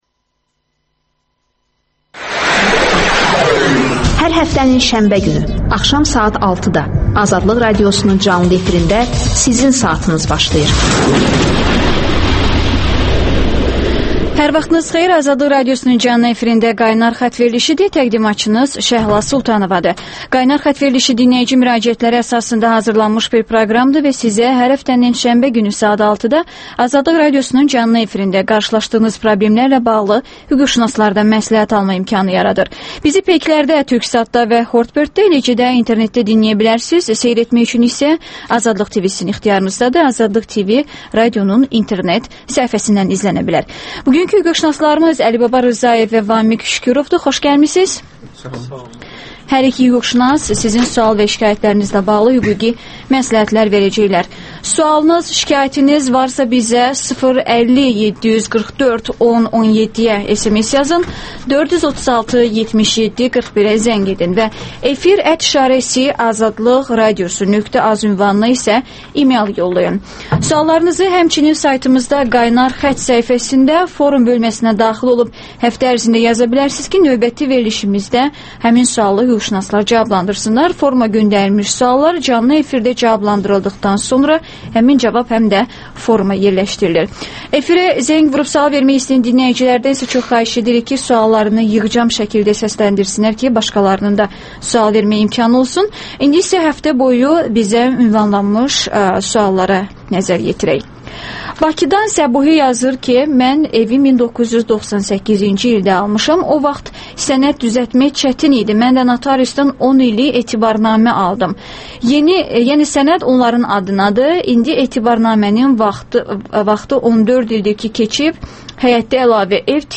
«Qaynar xətt» telefonunda dinləyicilərin suallarına hüquqşünaslar cavab verir.